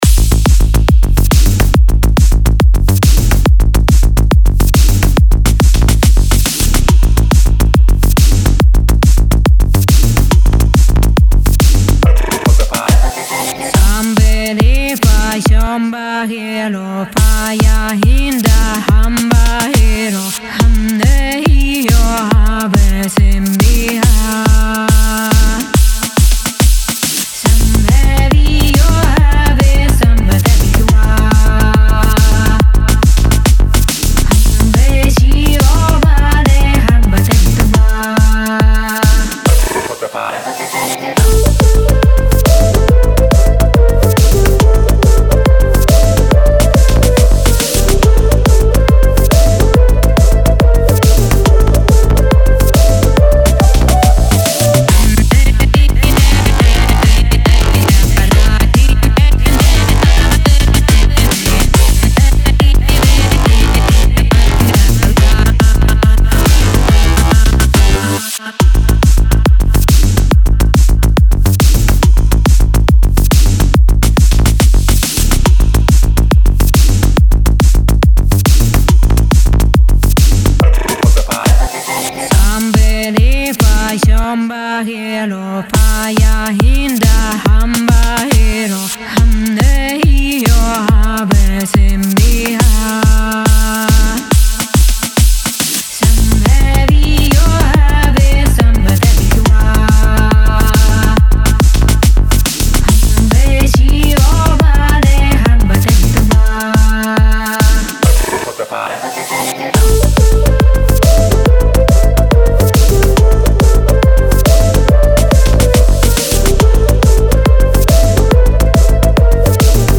Подкину немного транса )
Естественно отрывок/набросок, незаконченный, как ремонт ) ЗЫ Простите, что не аплифтинг (подмигивающий смайл)